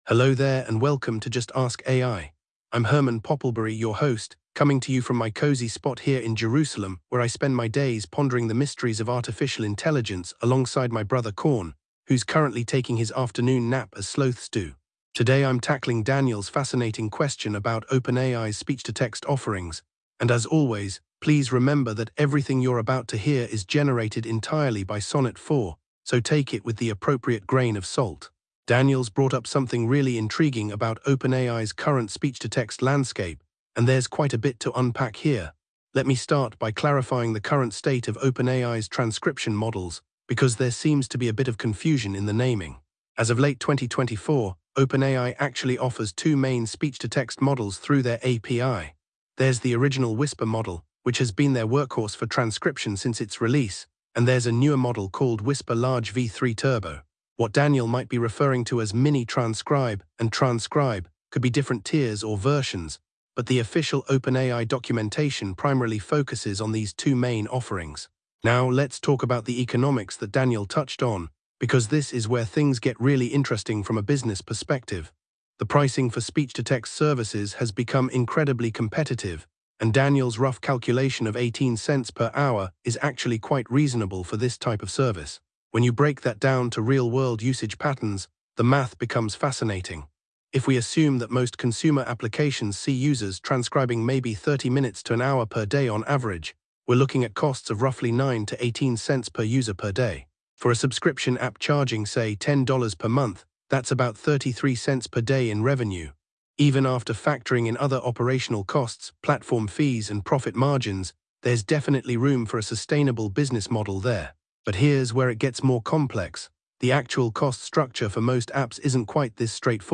AI-Generated Content: This podcast is created using AI personas.
This episode was generated with AI assistance. Hosts Herman and Corn are AI personalities.